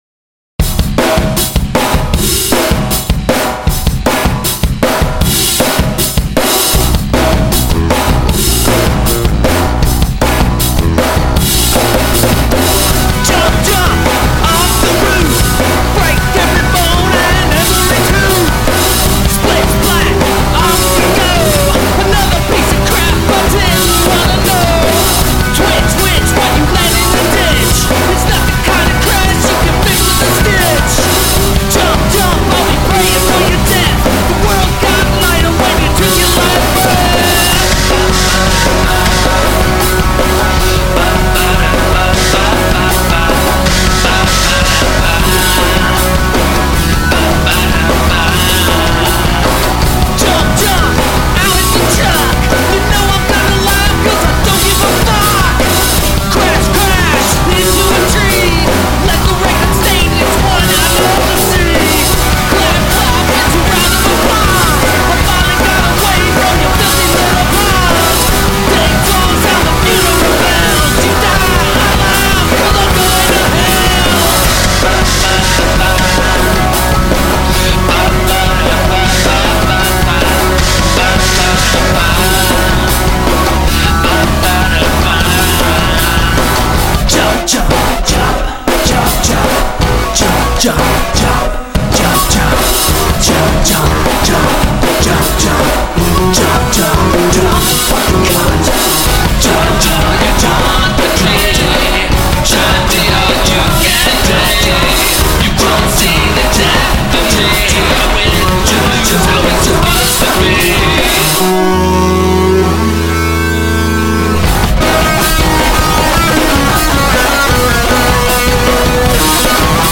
Punk
Rock & Roll